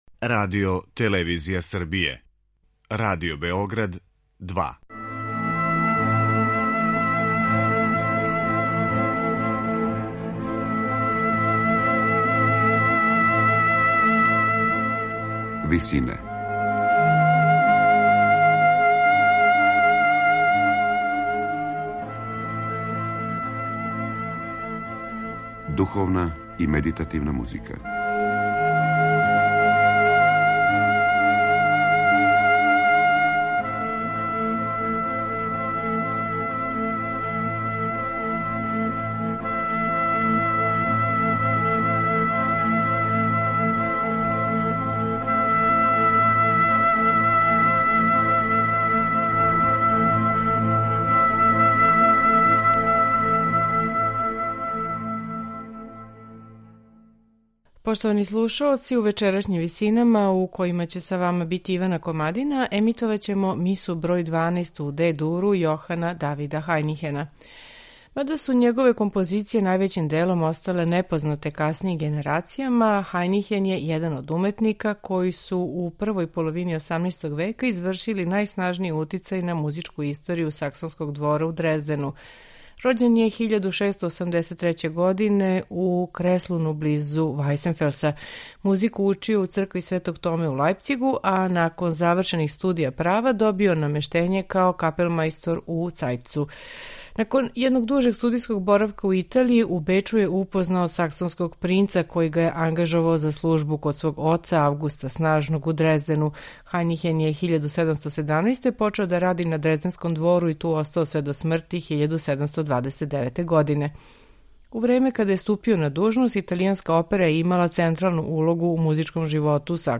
Хајнихенова Миса број 12 у Де-дуру, коју ћемо слушати у вечерашњим Висинама , типичан је пример развијене барокне мисе. Сваки од ставова ординаријума подељен је у мање одсеке различитог трајања, музичке структуре и оркестрације.
сопран
алт
тенор
басови
камерног хора